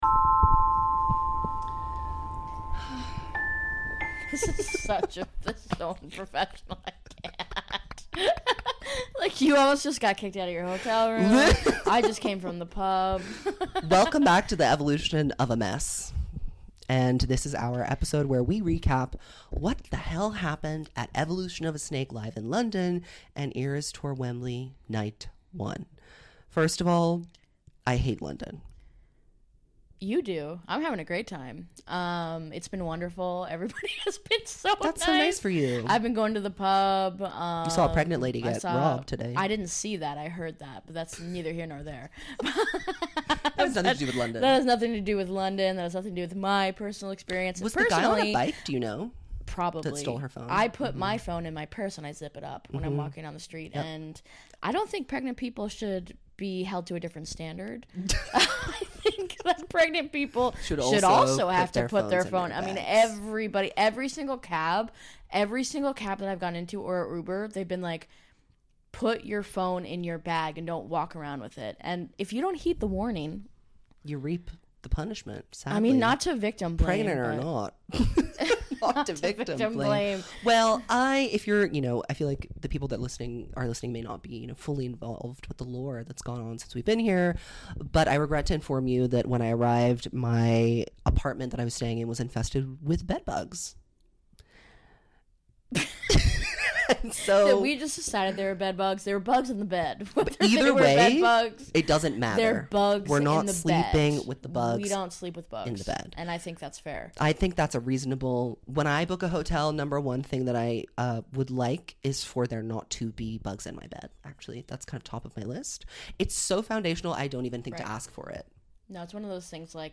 In today’s extra special Taylor Talk, we are together live and in-person (full video on Patreon) to discuss the highlight of our podcasting lives so far: Evolution of a Snake LIVE! in London (also available only on the Patreon.) We regale you with tales of pregnant ladies getting robbed, bed bugs in a ship container hotel, and the hostility we encountered from a children’s theatre director. Last, we give you our detailed review of the NEW Eras Tour set, including our first experience of Tortured Poets live and the greatest surprise song combos we could’ve dreamed of.